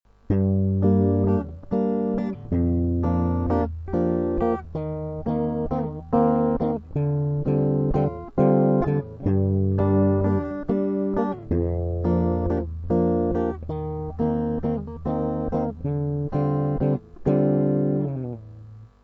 Вступление, проигрыш (Gm-Gm7/F-Cm7/Eb-Dm11):